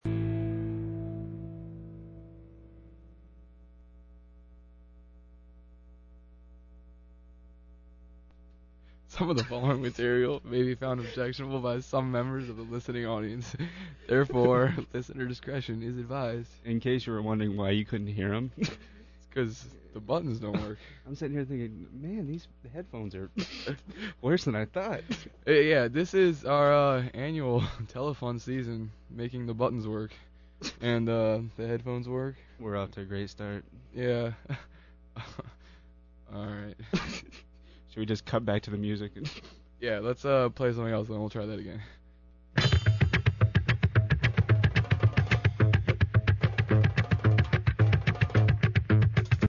• Listen to how positive and upbeat each programmer is when talking about the fundraiser.